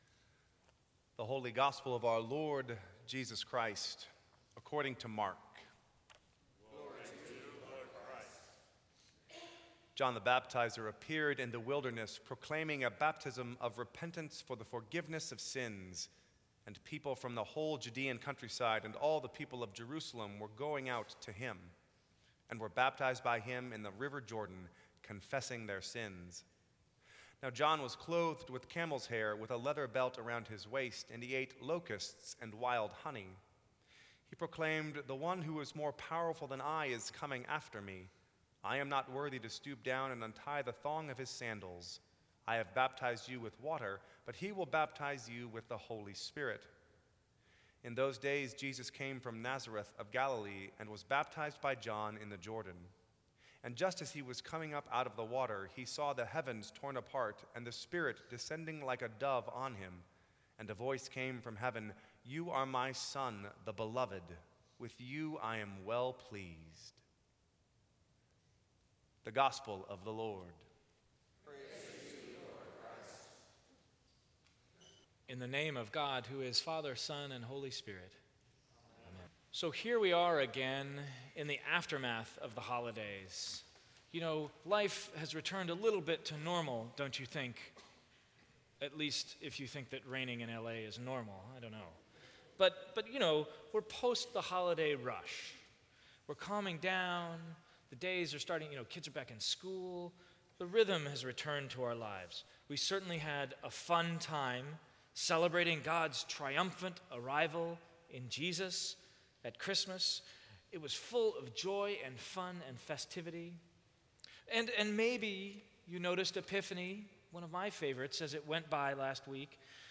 Sermons from St. Cross Episcopal Church Why was Jesus Baptized?